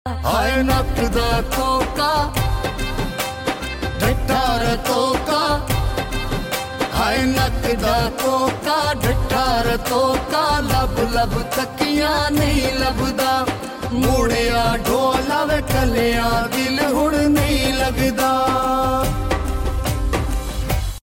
جلسہ حویلیاں